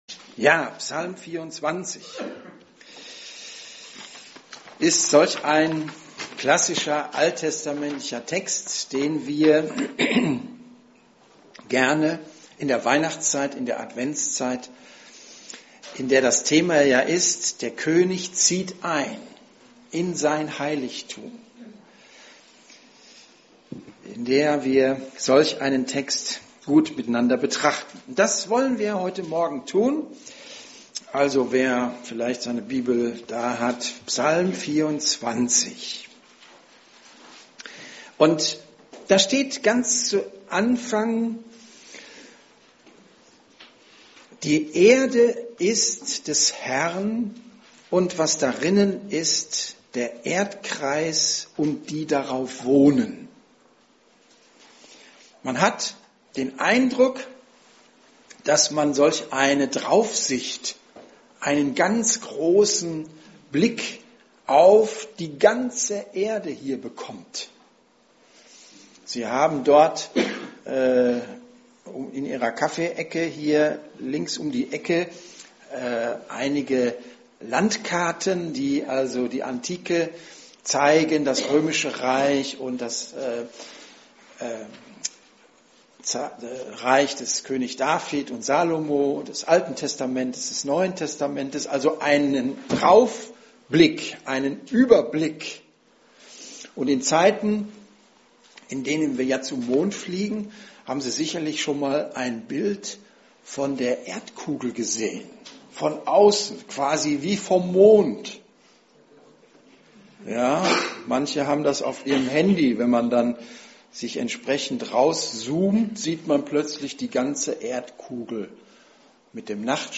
Predigten Anderer (nicht EfG Bonn)